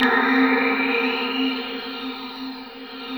21PAD 02  -R.wav